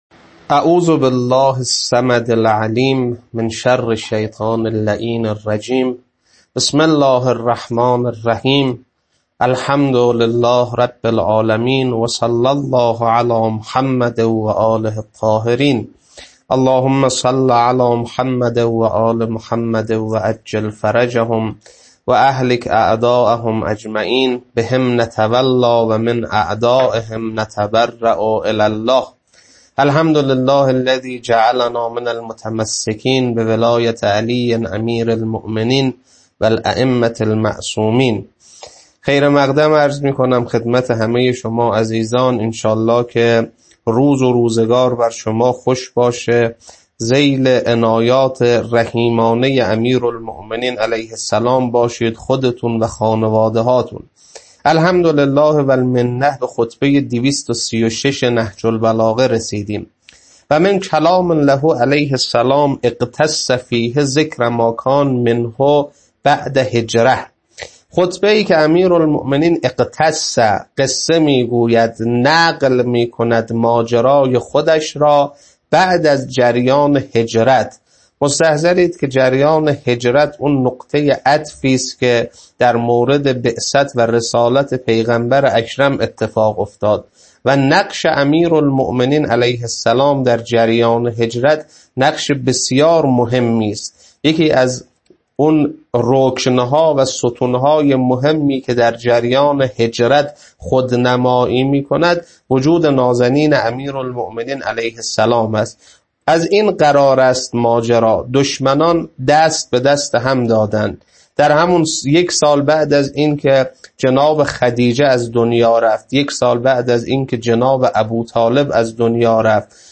خطبه-236.mp3